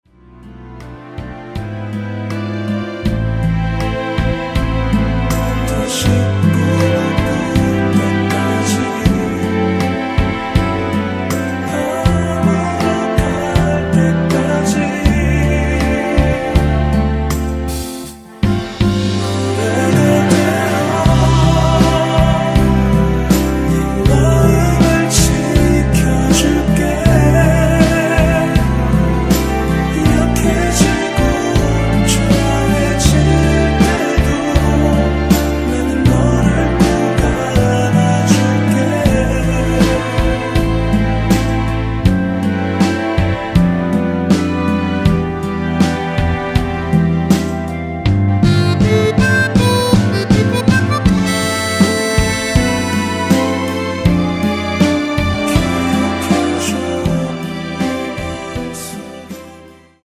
원키에서(-1)내린 멜로디와 코러스 포함된 MR입니다.(미리듣기 확인)
앞부분30초, 뒷부분30초씩 편집해서 올려 드리고 있습니다.
중간에 음이 끈어지고 다시 나오는 이유는